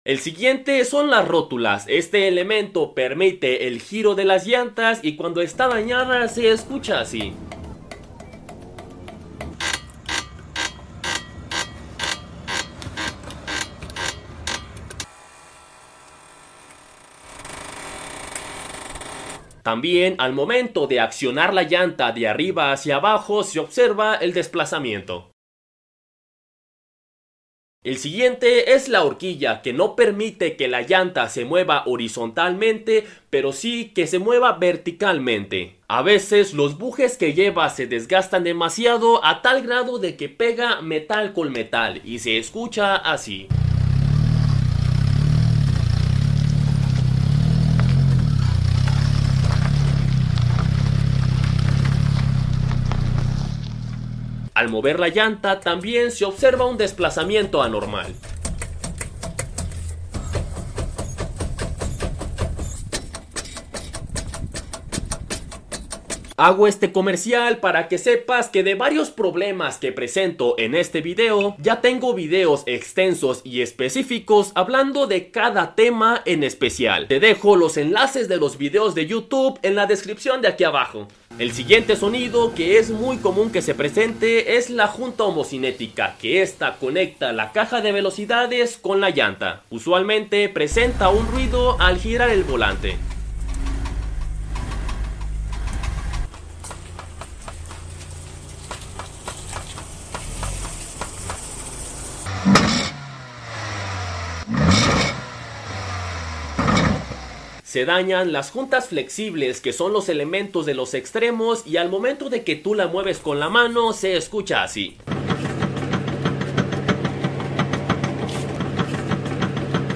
21 SONIDOS de un AUTO sound effects free download
21 SONIDOS de un AUTO DAÑADO | Escucha el RUIDO de la SUSPENSIÓN, DIRECCIÓN, MOTOR, CAJA Y FRENOS